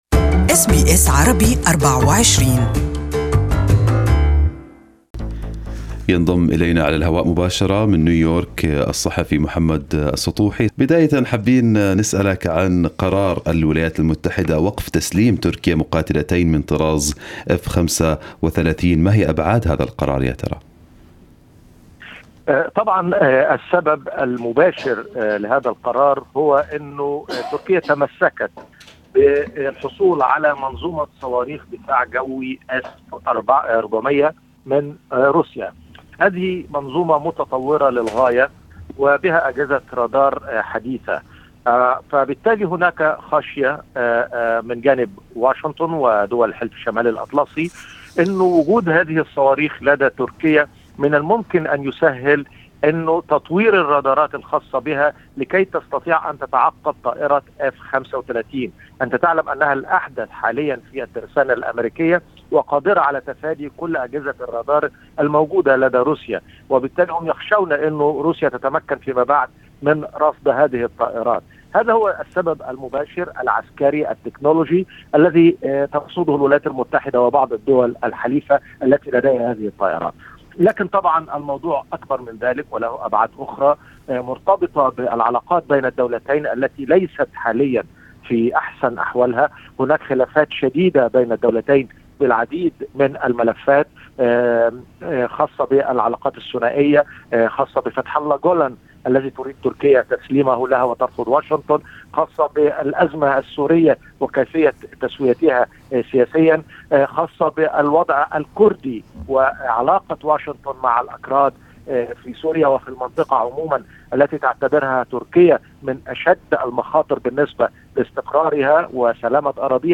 Our correspondent in Washington has the details